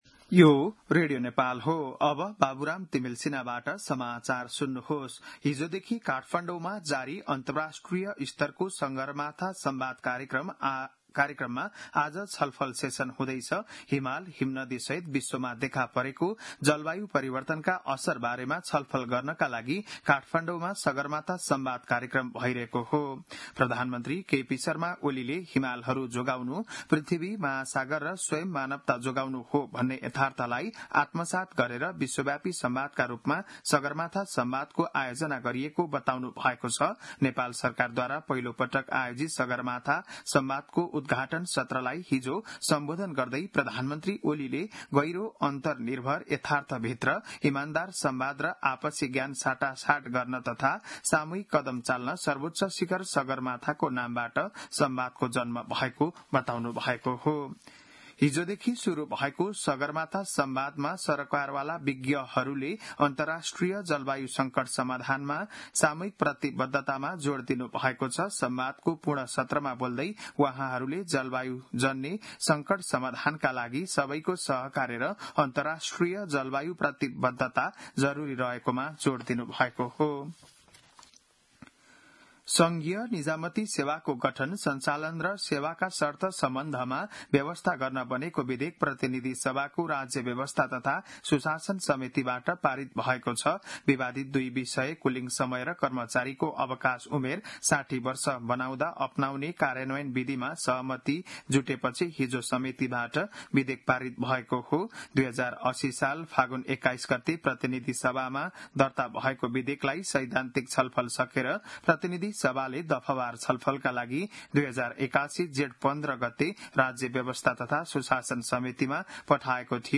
बिहान ११ बजेको नेपाली समाचार : ३ जेठ , २०८२
11-am-Nepali-News-4.mp3